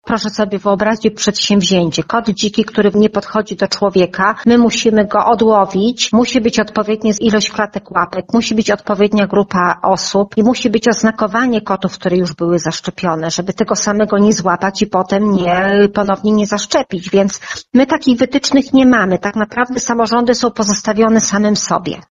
– Szczepienia rozpoczniemy od miejsc dokarmiania takich kotów – mówi wiceprezydent Zamościa, Marta Pfeifer.